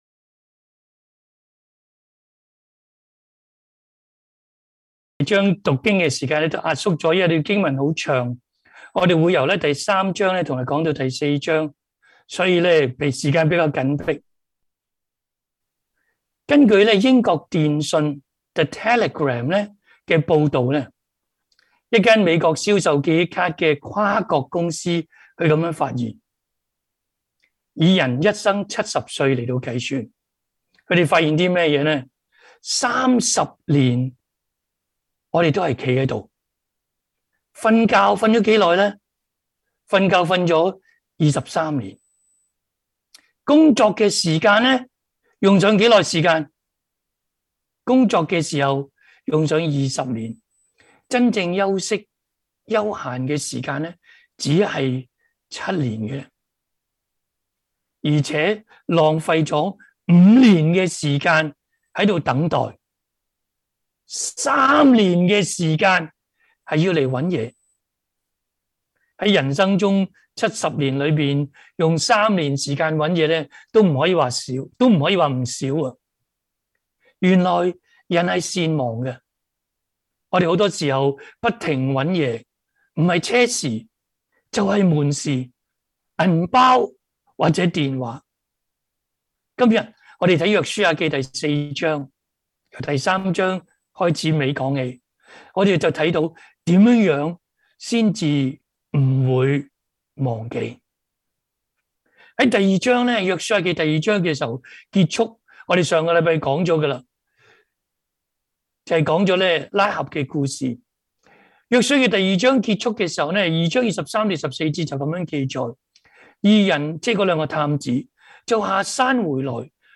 Canto0815-sermon.mp3